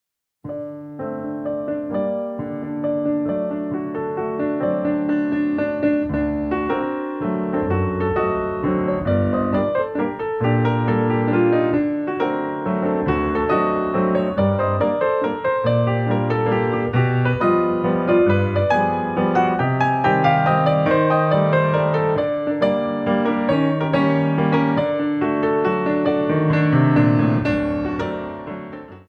4*8 + 2*8 : 3T
mazur